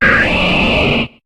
Cri de Laggron dans Pokémon HOME.